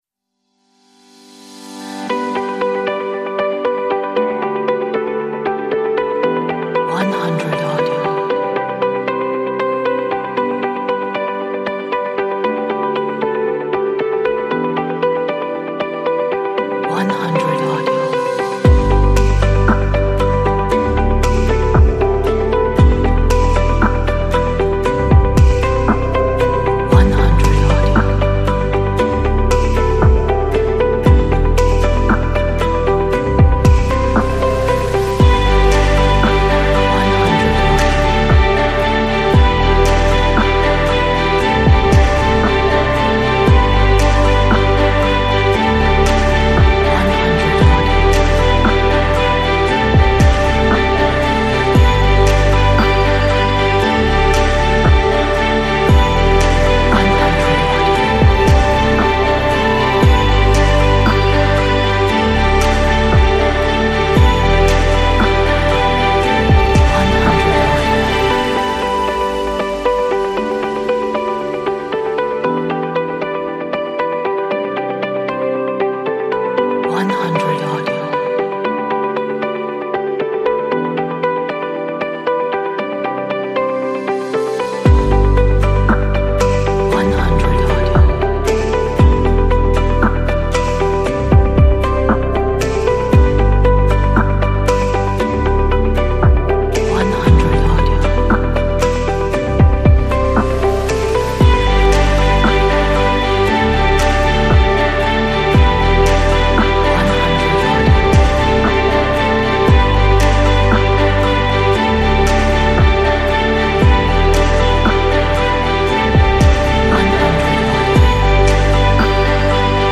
a nice pop upbeat inspiring track